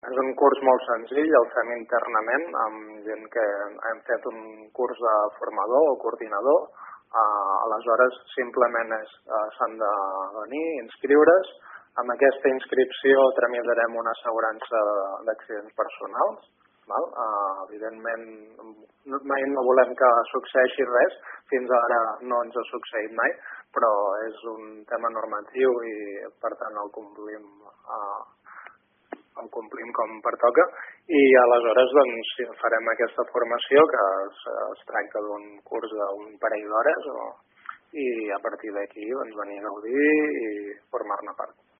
Ho explica, en delcaracions a aquesta emissora